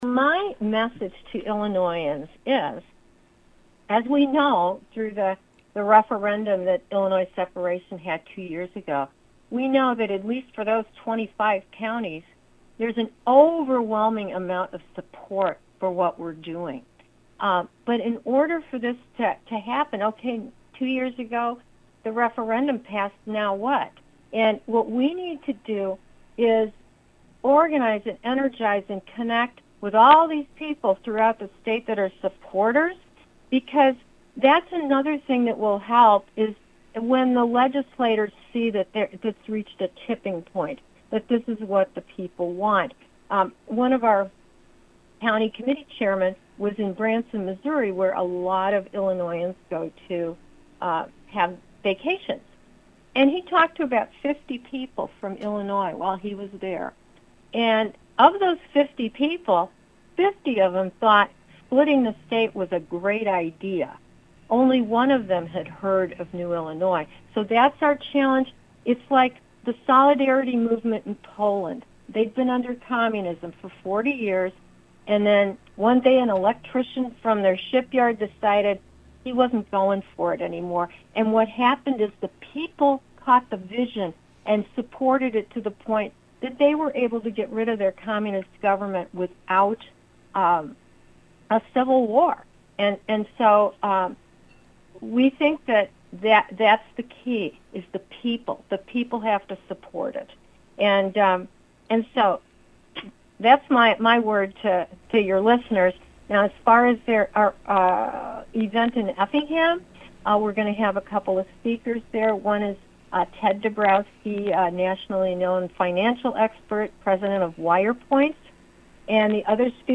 new-il-interview-part-6.mp3